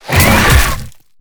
Sfx_creature_chelicerate_bite_03.ogg